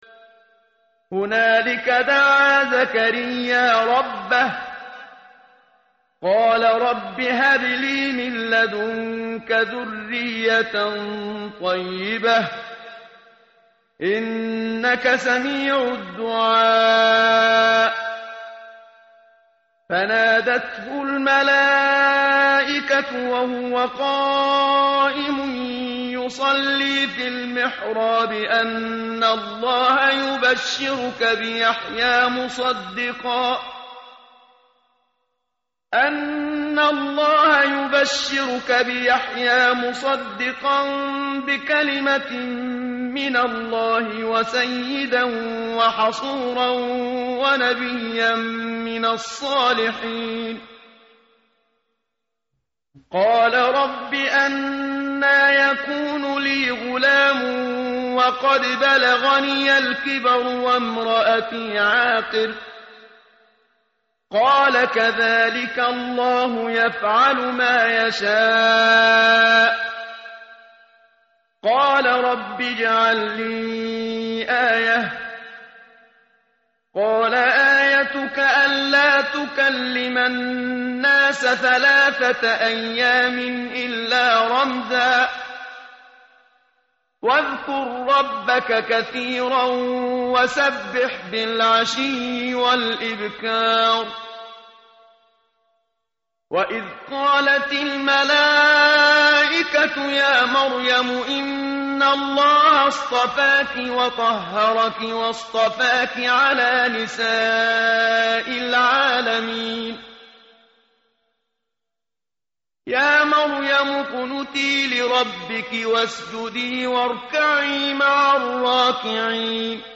tartil_menshavi_page_055.mp3